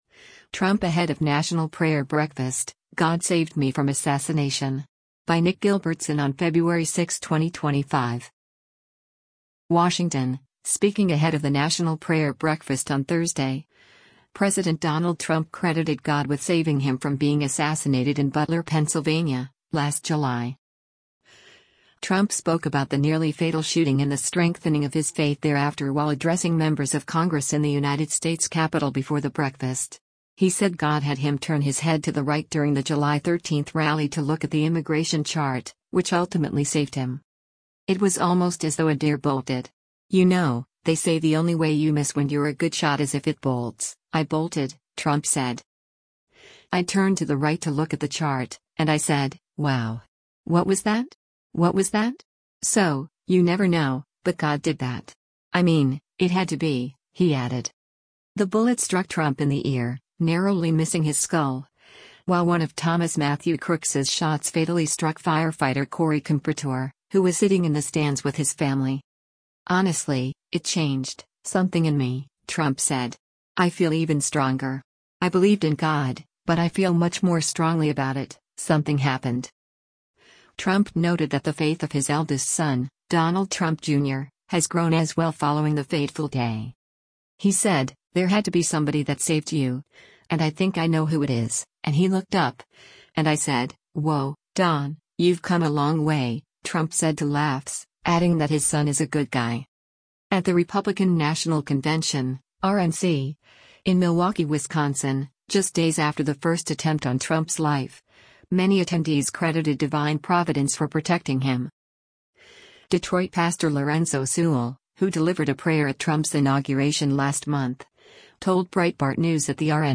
President Donald Trump speaks at the National Prayer Breakfast at the U.S. Capitol on Febr